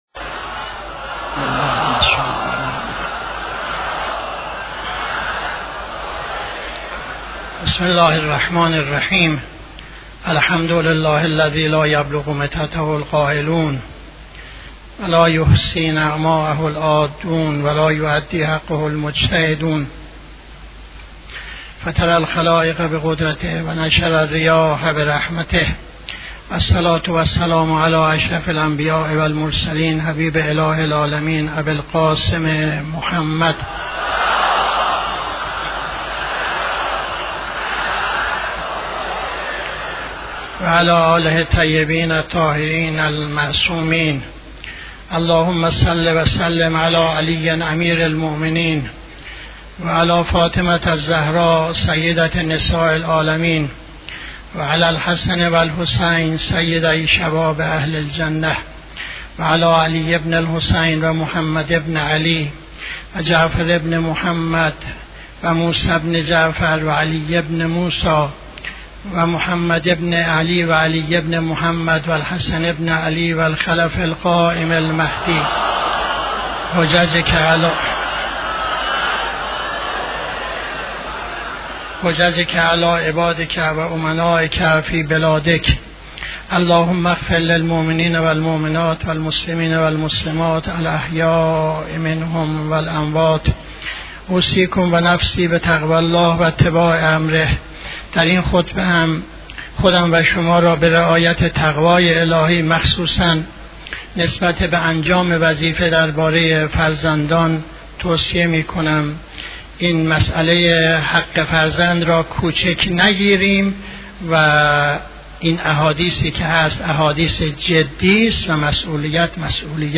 خطبه دوم نماز جمعه 07-02-80